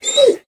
wolf-hit-2.ogg